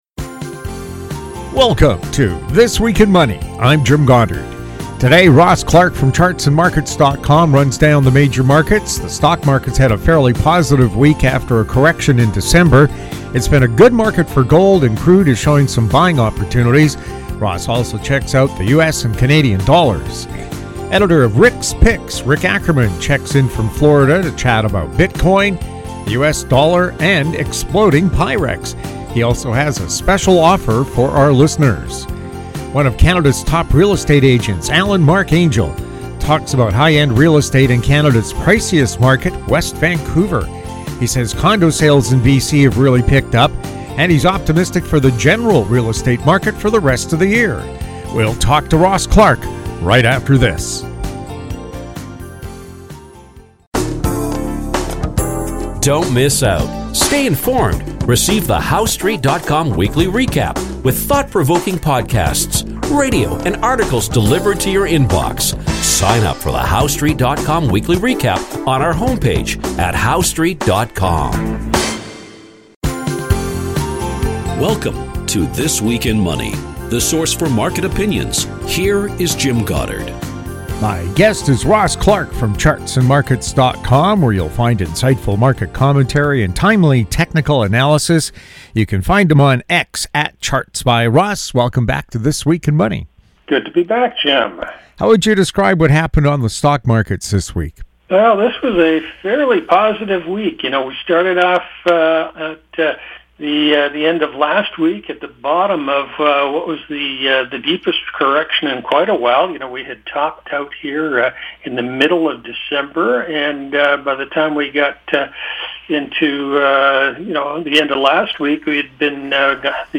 January 25, 2025 | This Week in Money This Week in Money Visit Show Archives This Week in Money presents leading financial news and market commentary from interesting, informative and profound guests. They are some of the financial world's most colorful and controversial thinkers, discussing the markets, economies and more!
New shows air Saturdays on Internet Radio.